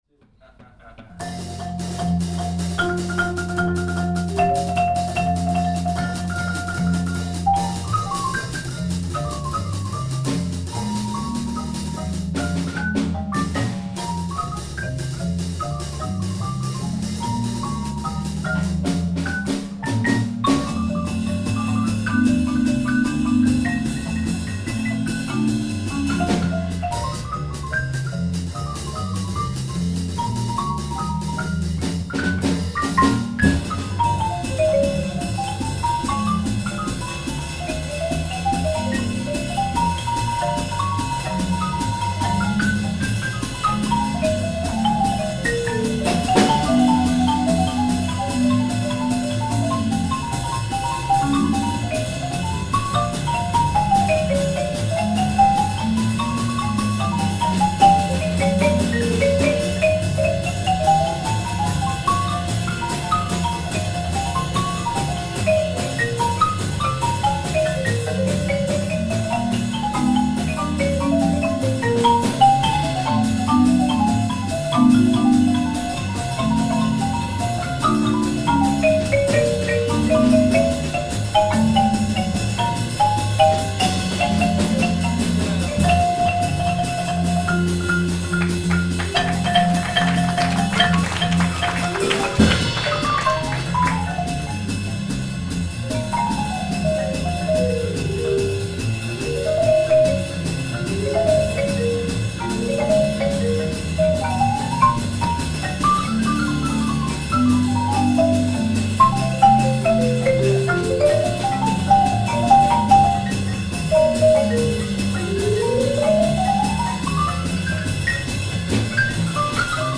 vibrafono
marimba
contrabbasso
batteria
GenereJazz
ProvenienzaAnzola Jazz Club Henghel Gualdi